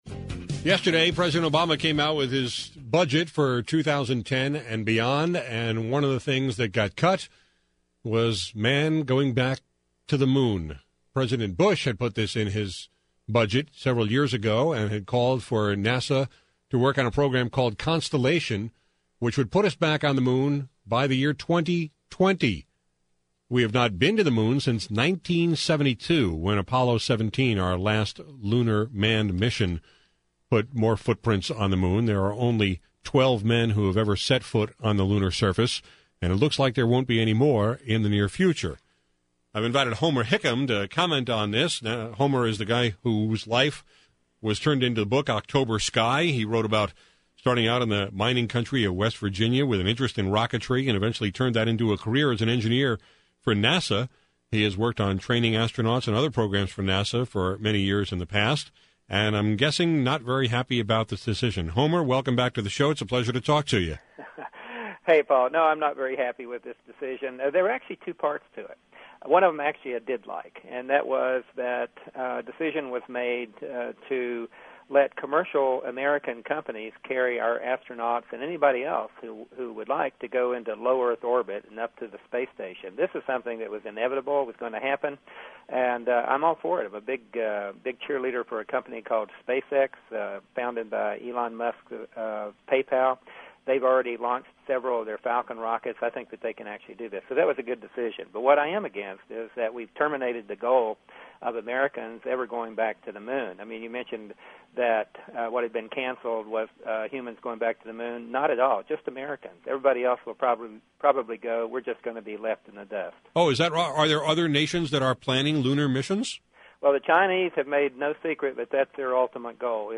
I talked about this sad scientific news with Homer Hickam, the former NASA engineer whose life story became the book and movie “October Sky.”